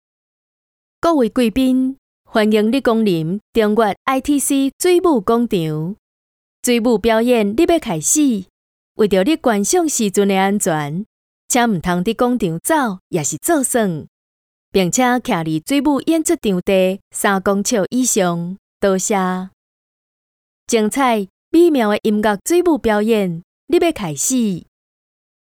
台語配音 國語配音 女性配音員
活動語音 _ 台語 _ 端莊、專業】中悅水舞廣場台語語音
✔ 高辨識度中音女聲，適合企業品牌廣告、銀行、食品、家電類產品旁白